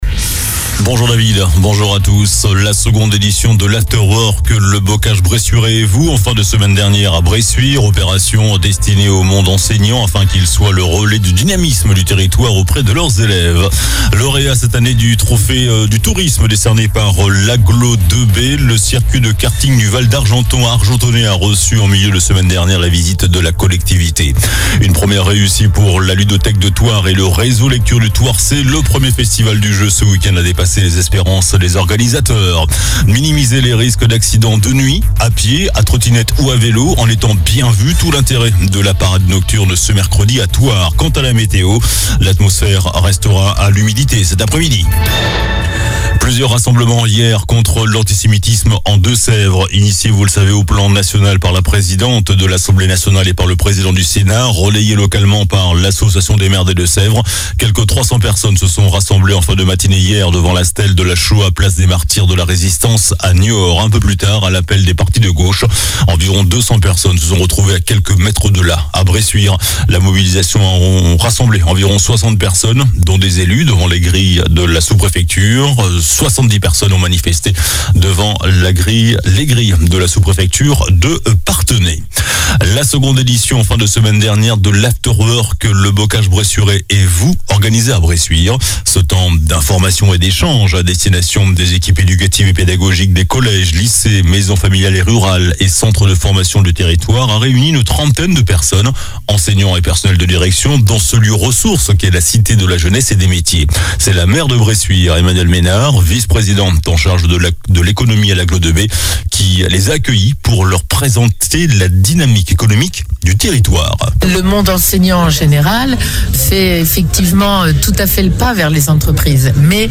JOURNAL DU LUNDI 13 NOVEMBRE ( MIDI )